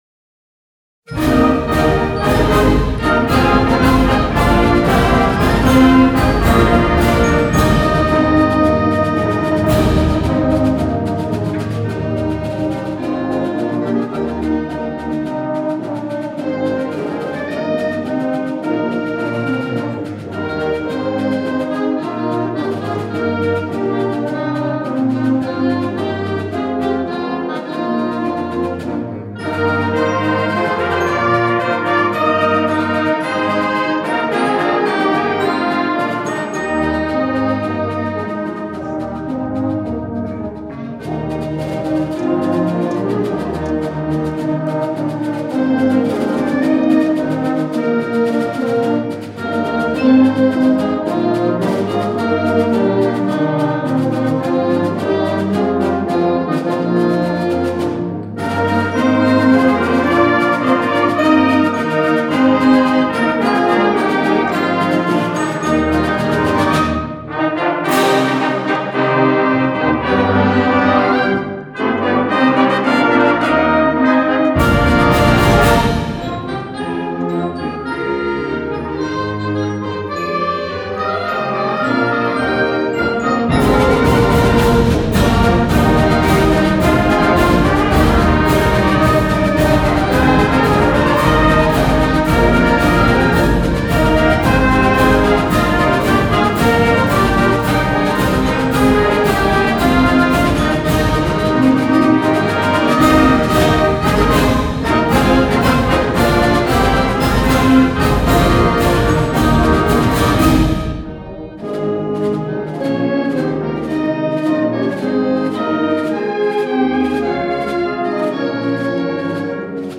Fall Band Concerts 6:30